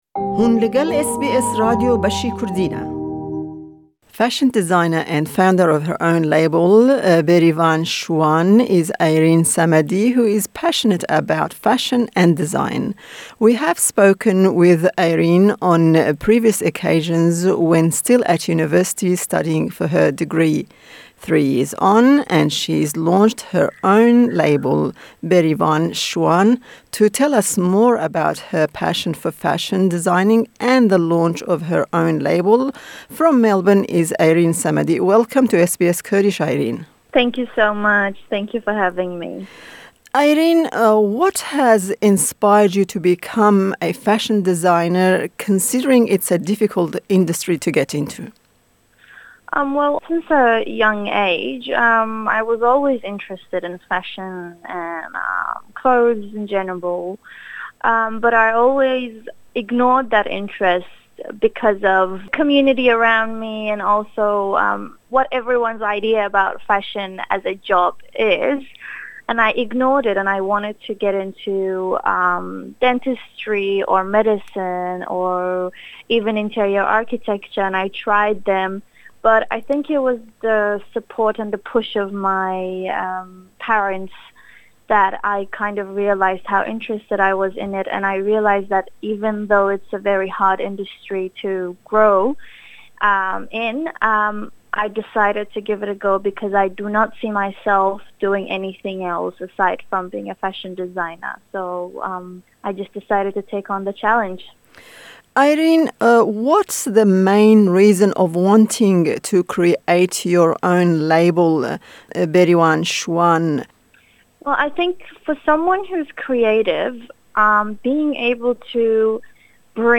Ji ber lawazbûna zimanê wê yê Kurdî hevpeyvîn bi zimanî Îngilîziy e.